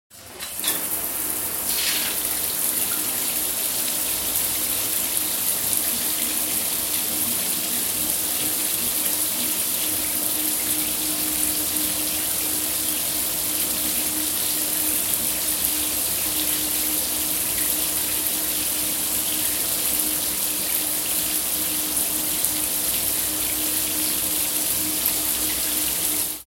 На этой странице собраны звуки, связанные с использованием шампуня: вспенивание, нанесение на волосы, смывание водой. Эти успокаивающие шумы подойдут для релаксации, ASMR-записей или фонового сопровождения.
Шум промытых волос